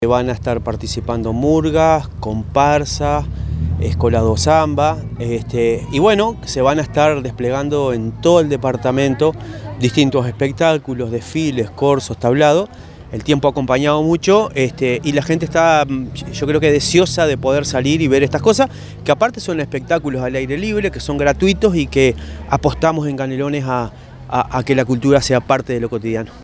marcelo_metediera_-_intendente_interino_de_canelones_3.mp3